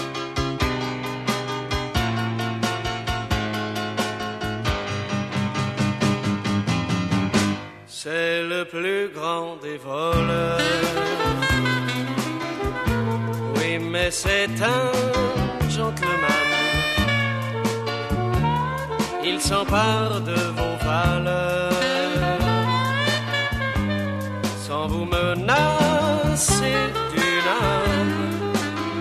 Un extrait de la deuxième version du générique (mp3) :